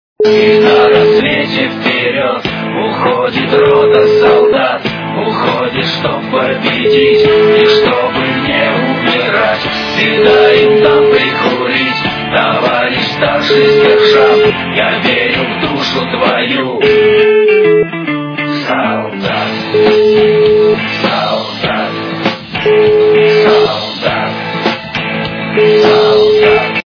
- русская эстрада
При заказе вы получаете реалтон без искажений.